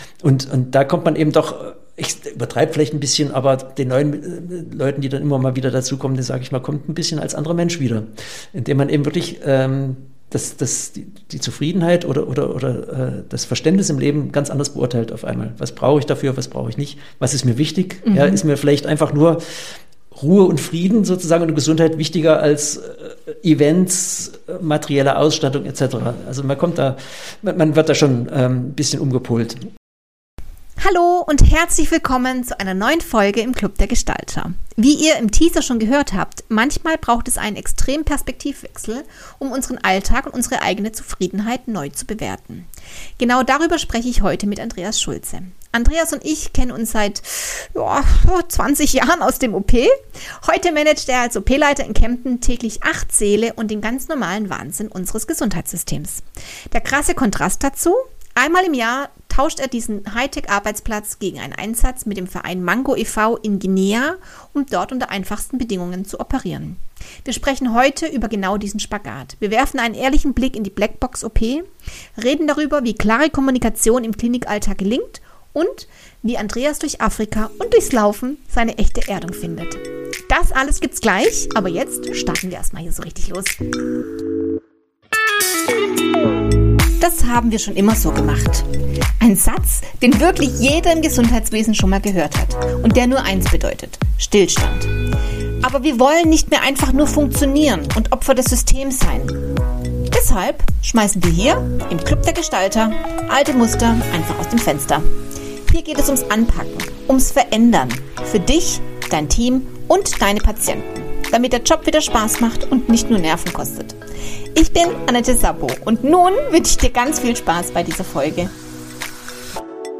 [Interview] Zwei Welten im OP: Von Kempten nach Guinea ~ Das haben wir schon IMMER so gemacht!